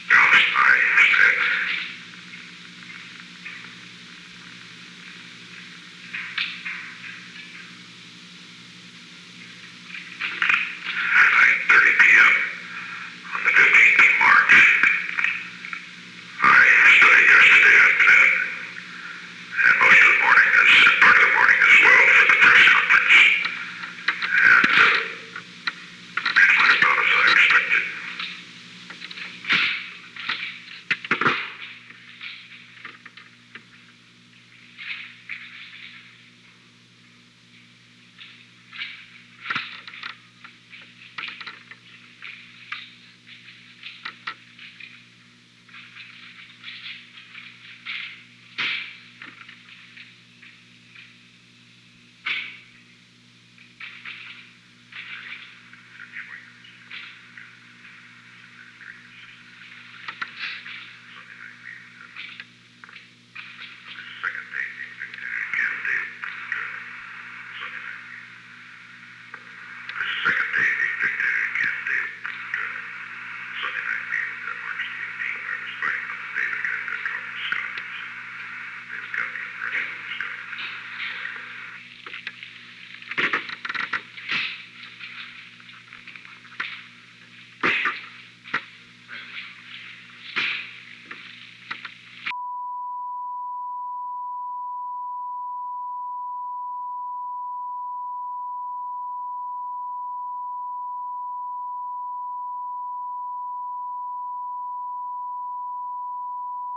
Secret White House Tapes
Location: Oval Office
The President played portions of a previously recorded dictabelt tape.
An unknown man entered at an unknown time after 11:20 am.